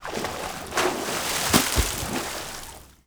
SPLASH_Movement_01_mono.wav